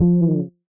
drug fog deco bass (slide).wav